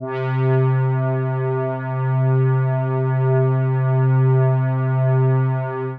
C3_trance_pad_1.wav